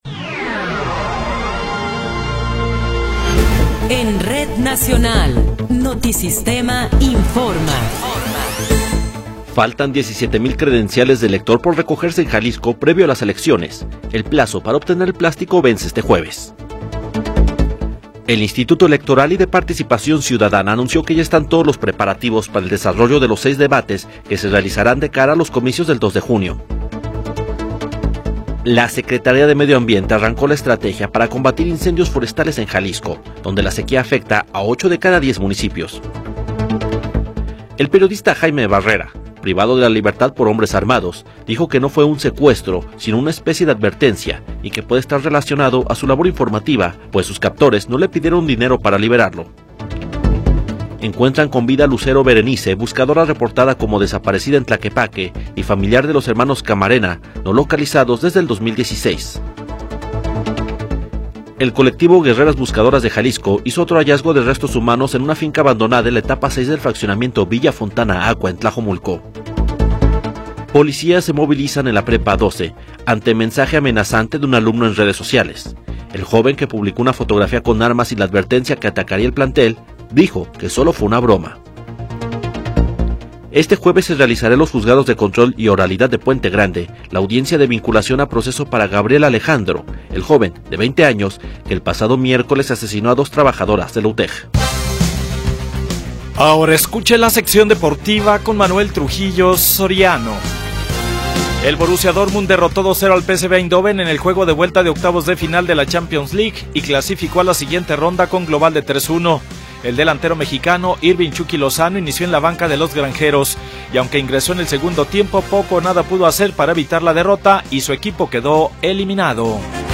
Noticiero 21 hrs. – 13 de Marzo de 2024
Resumen informativo Notisistema, la mejor y más completa información cada hora en la hora.